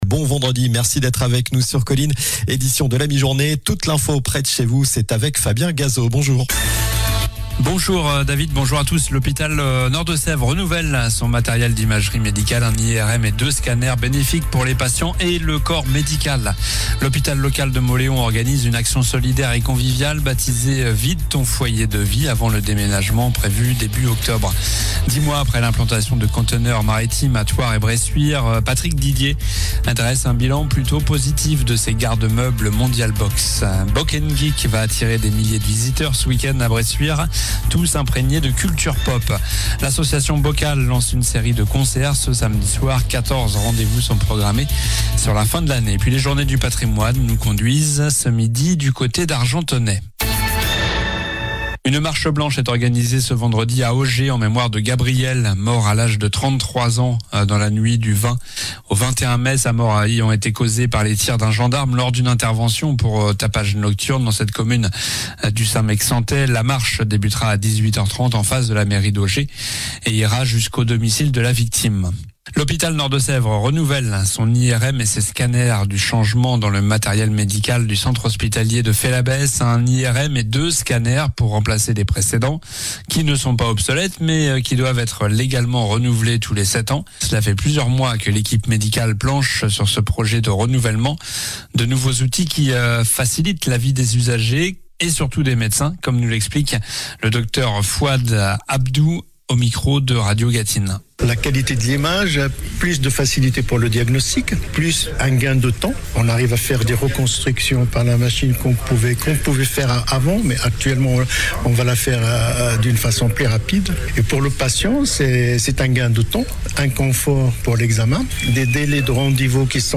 Journal du vendredi 19 septembre (midi)